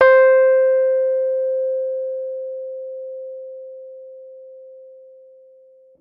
Rhodes_MK1
c4.mp3